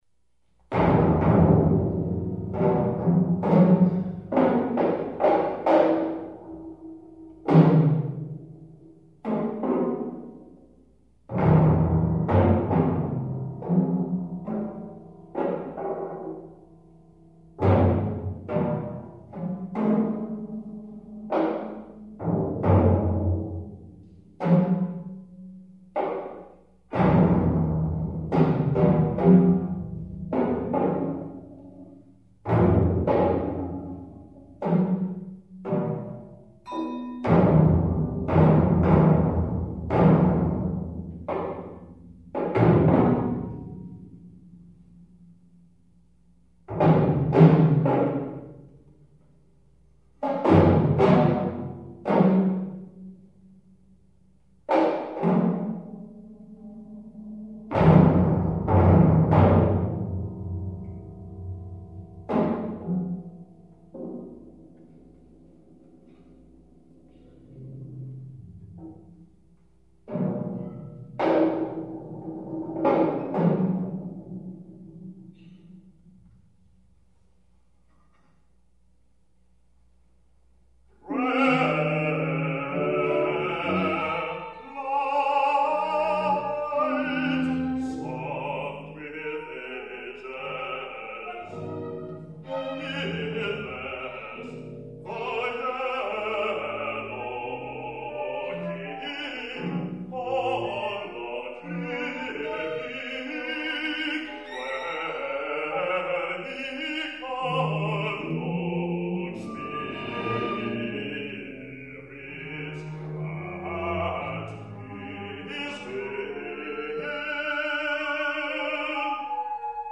a work for the stage